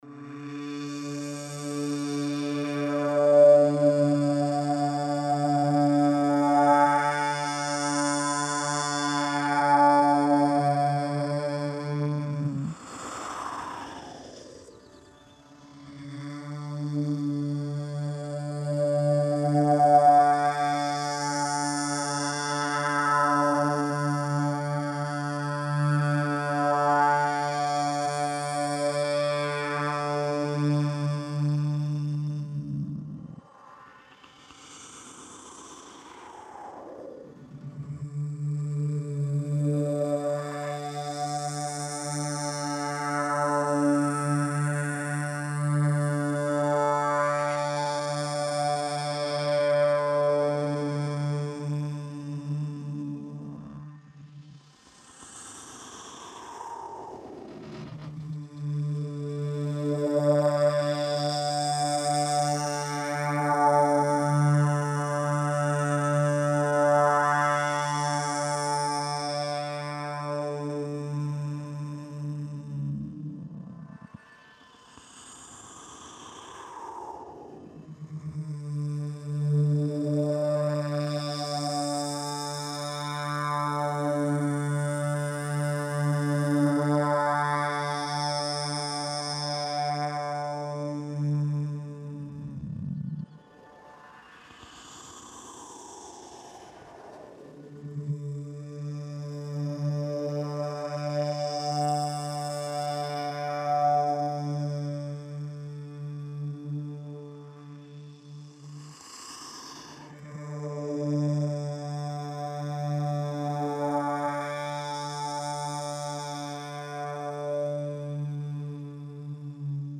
Soundscape
ambient soundtrack loop